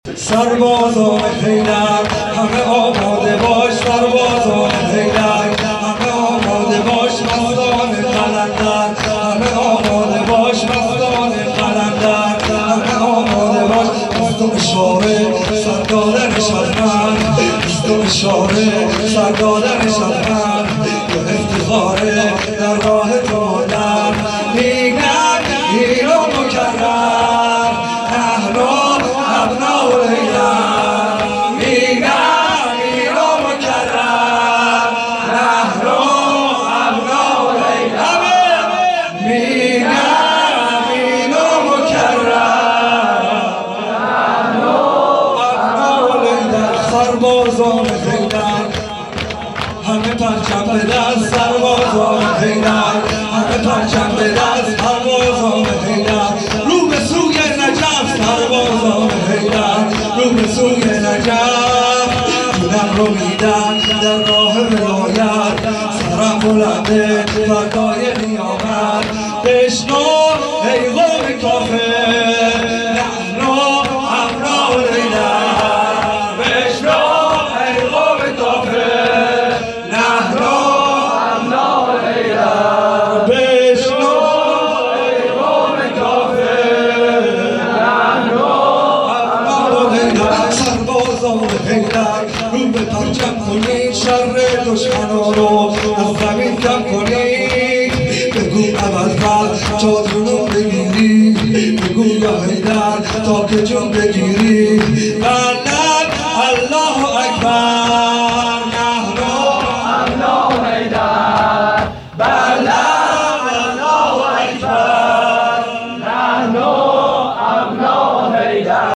شور
توضیحات: هیئت صادقیون حوزه علمیه زابل